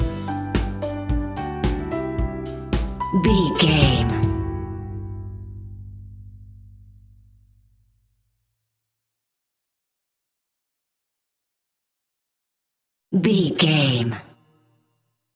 Motivation Business Pop Rock Music Stinger.
Ionian/Major
pop rock
indie pop
energetic
uplifting
instrumentals
upbeat
groovy
guitars
bass
drums
piano
organ